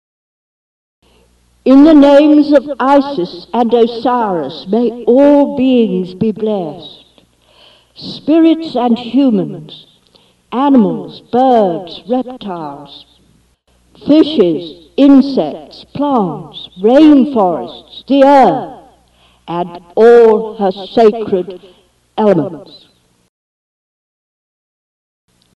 Blessing: